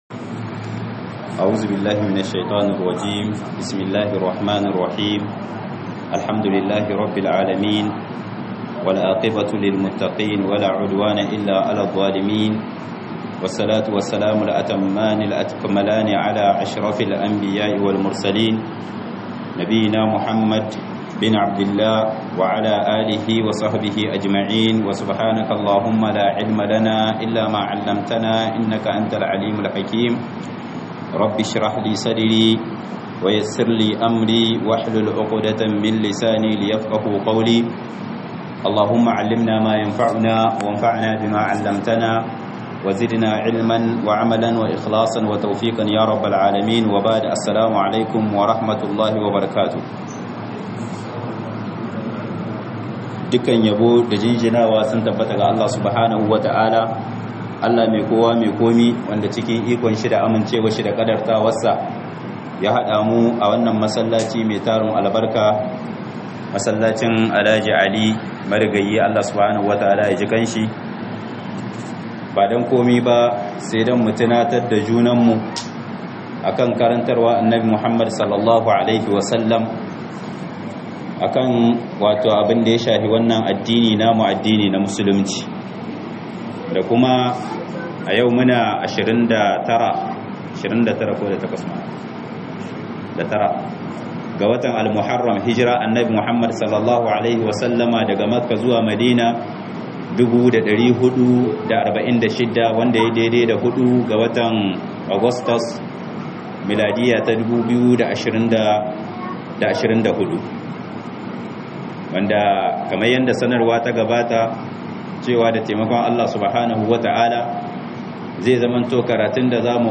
MUHIMMANCIN SALLAH - MUHADARA